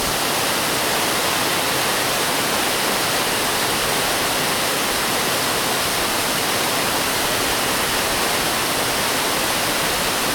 waterfall-2.ogg